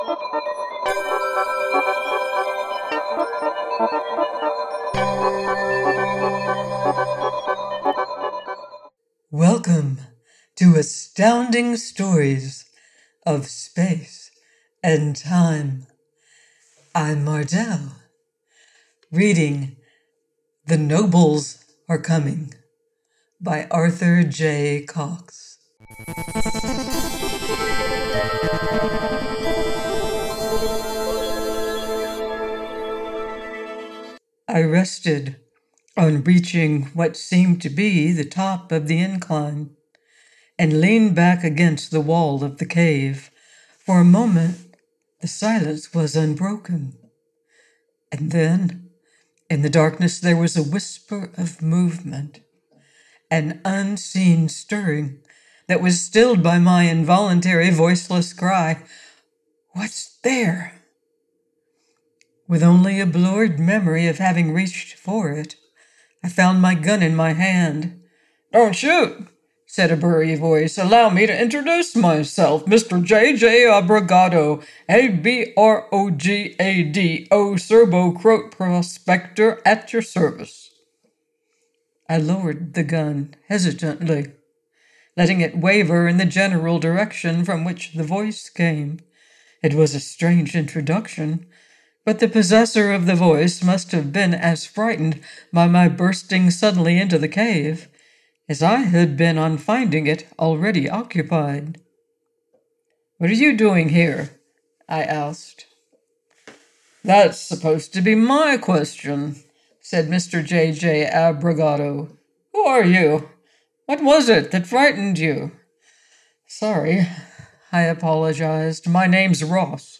The Nobles are Coming by Arthur J Cox - AUDIOBOOK